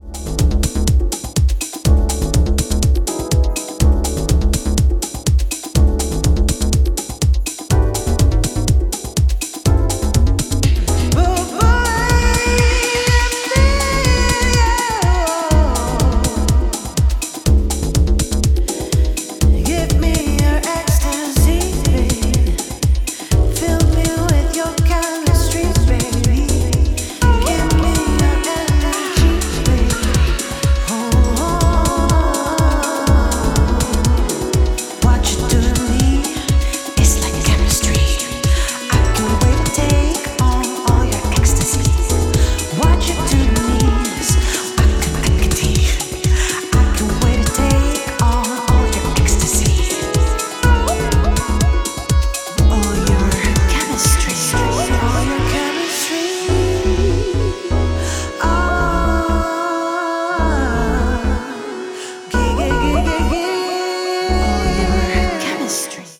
vocal remix